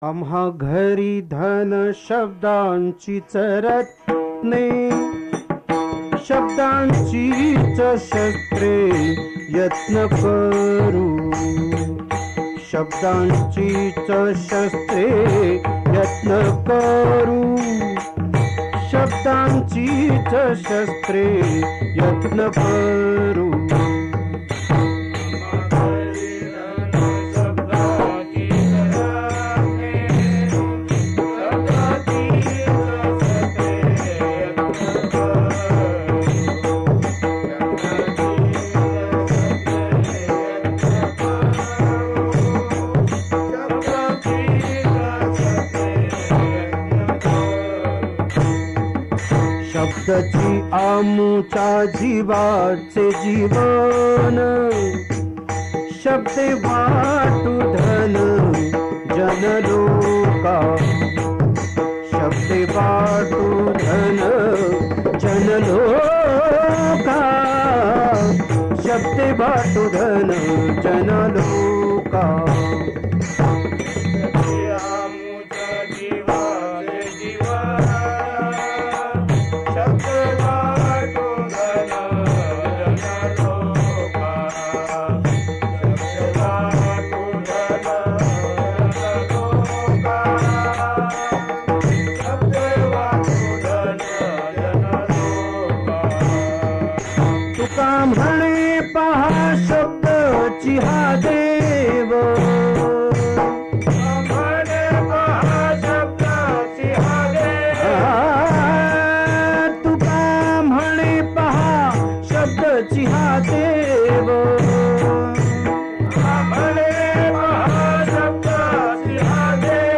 भजन - अभंग श्रवण
पखवाज